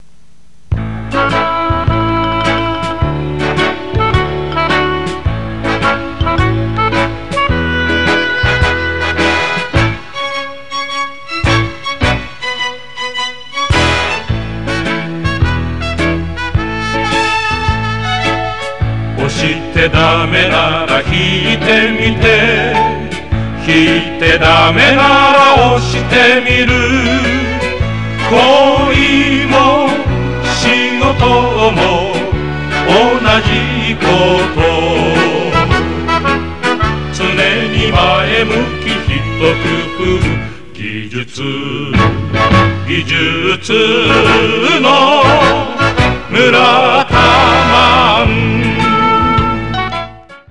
List_R02 常唱曲目《村田人小曲》（1979年）